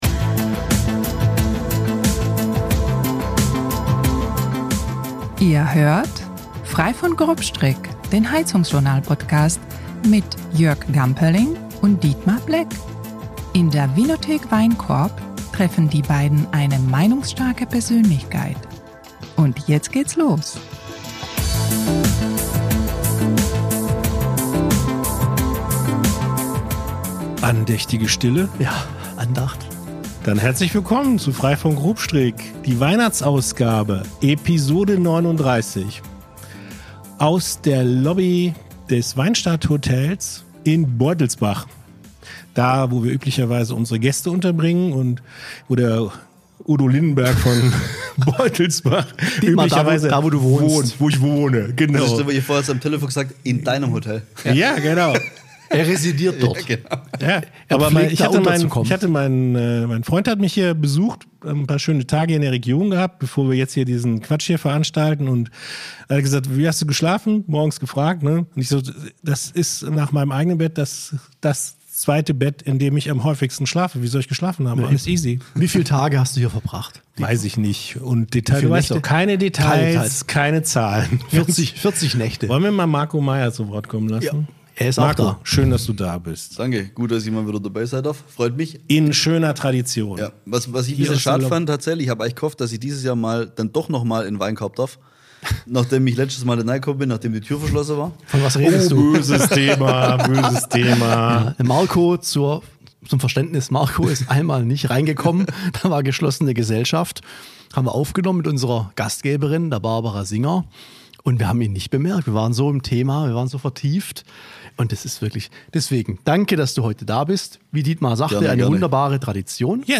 Locker bis launig, meinungsstark und informativ, das ist Frei von Grobstrick, der HeizungsJournal- Podcast.